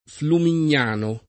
[ flumin’n’ # no ]